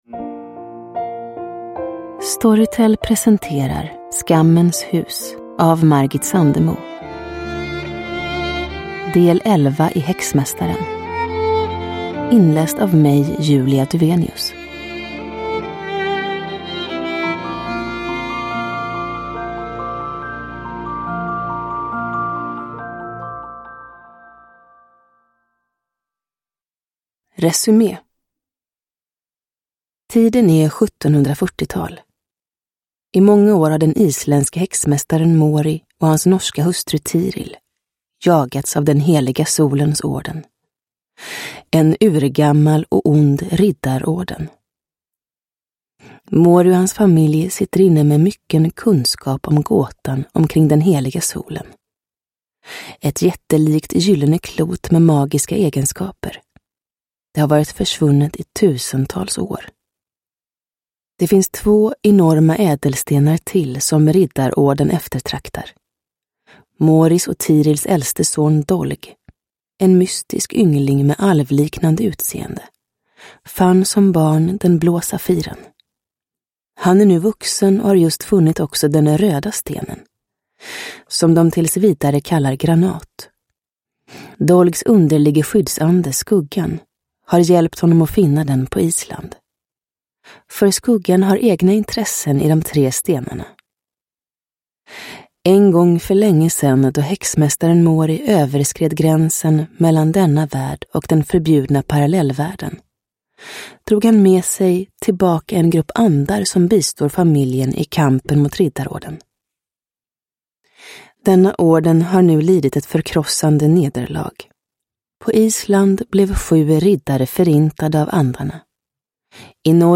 Skammens hus – Ljudbok – Laddas ner
Uppläsare: Julia Dufvenius